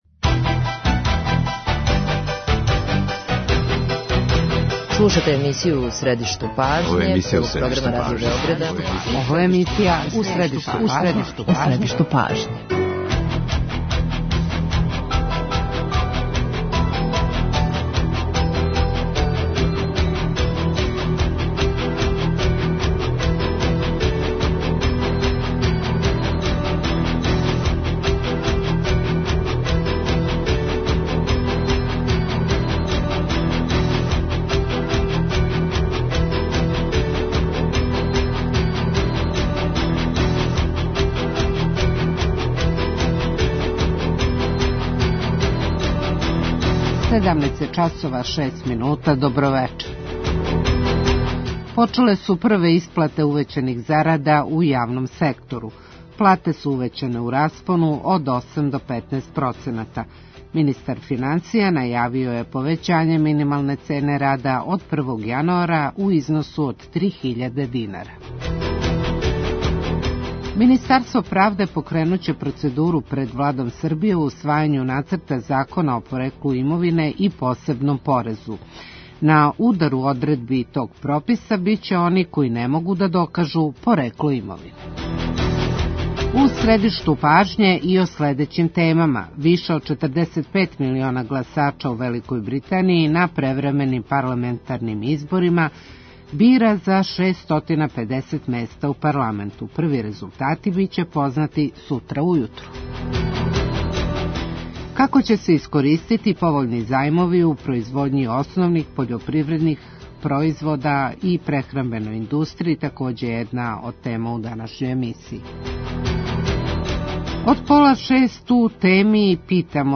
Гост емисије је Страхиња Секулић, директор Агенције за реституцију.